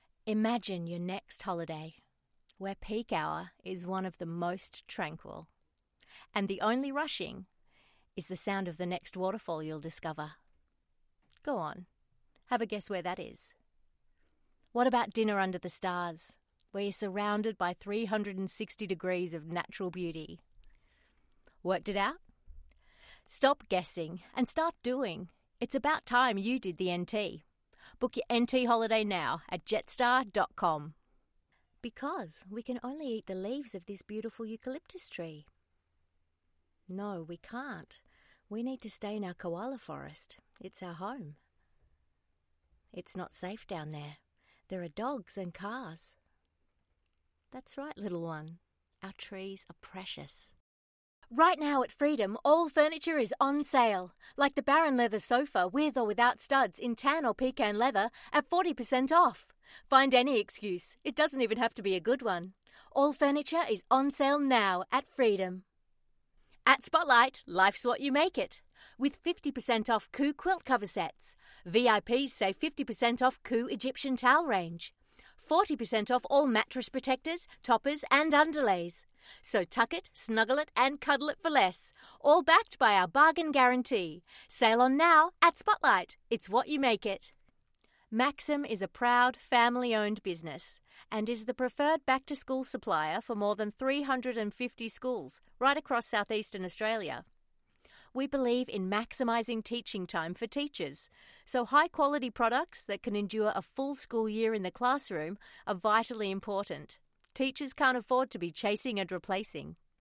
Female
English (Australian)
A bright, intelligent and natural voice with the ability to interpret nuance and complex characters.
Radio Commercials
Voice Sample For Commercials
Words that describe my voice are bright, Natural, sincere.